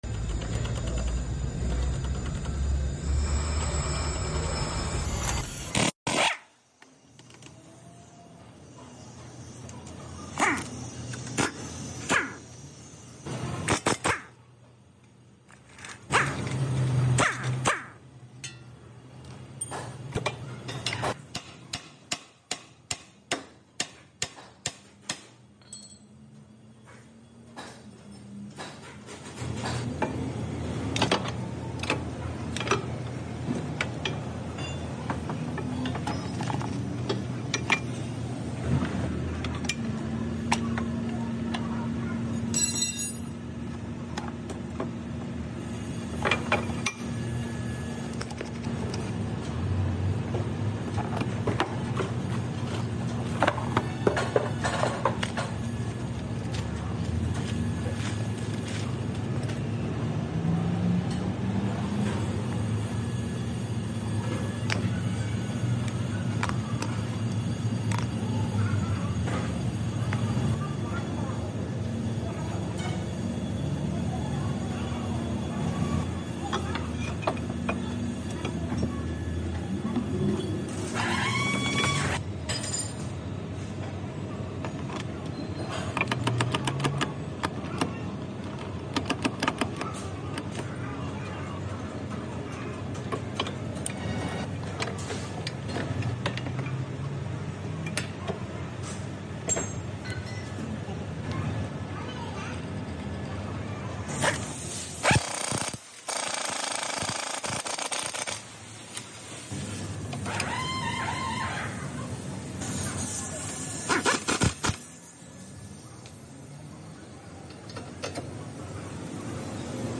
Mazda Front Cv Joint Nosie Sound Effects Free Download